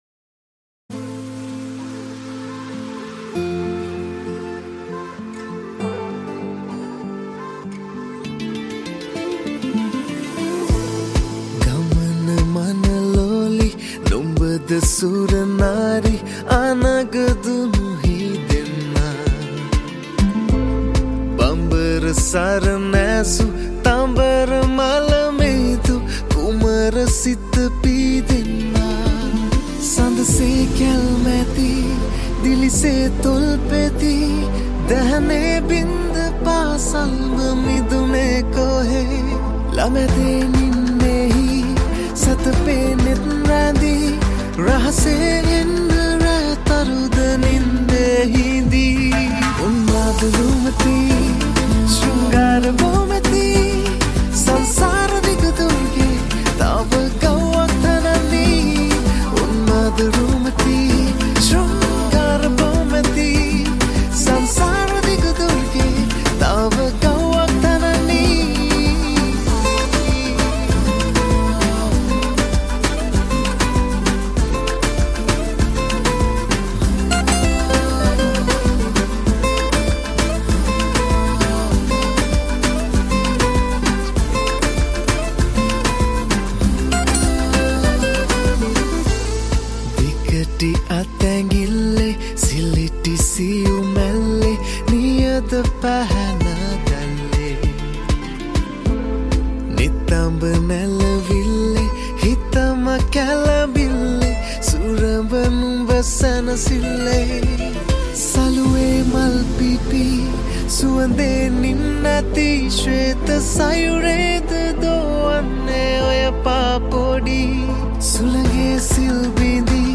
High quality Sri Lankan remix MP3 (3.9).